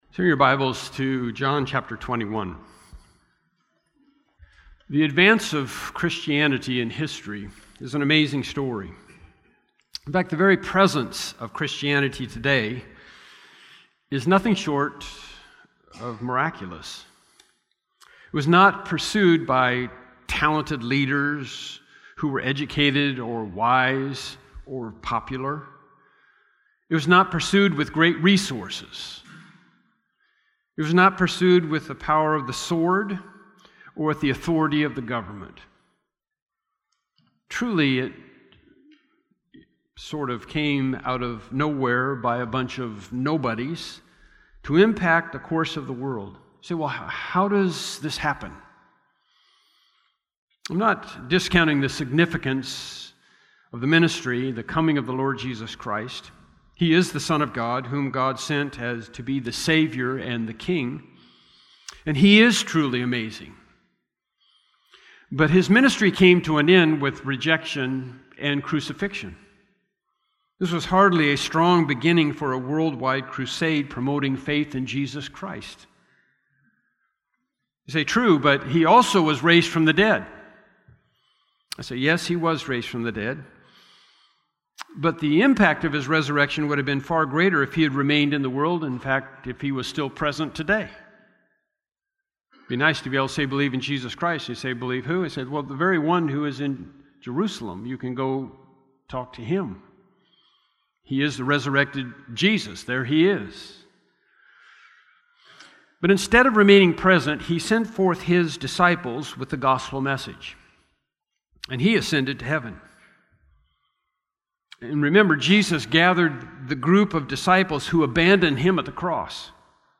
Bible Text: John 21:1-14 | Preacher